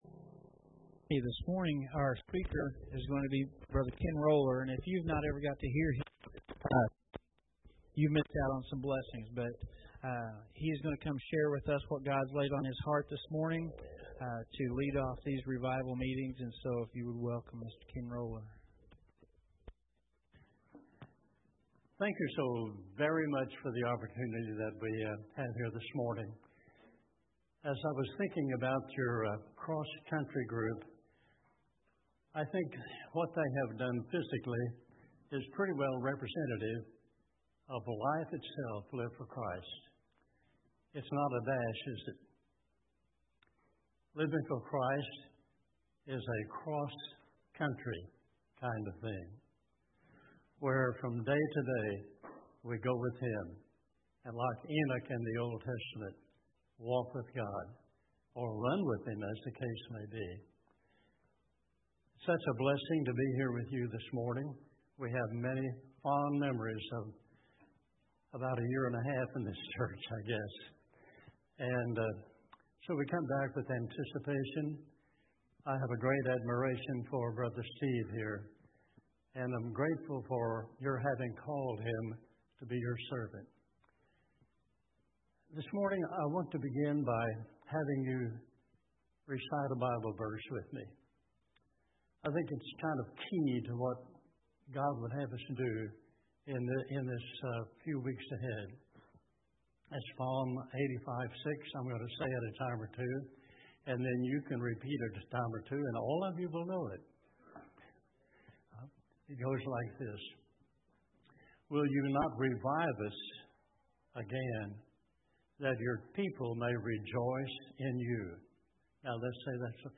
Revival Message